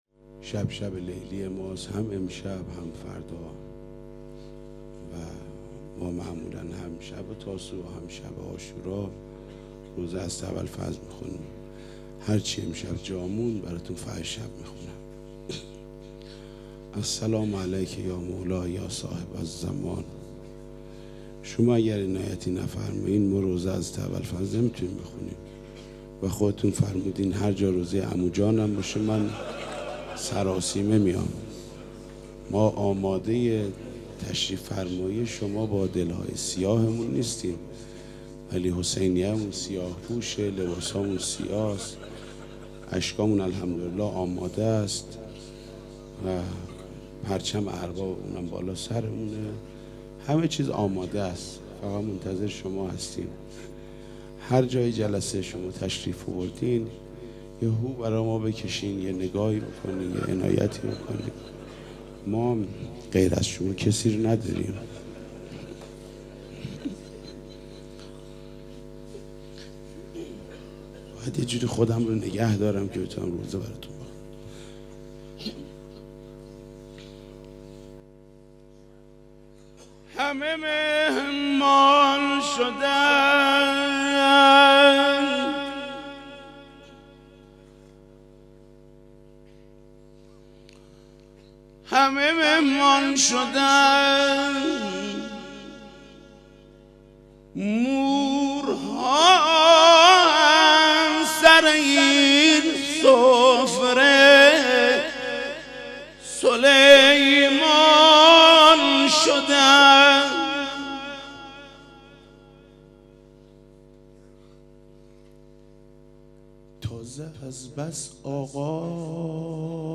هیأت رایه العباس(ع) رزمندگان شمیرانات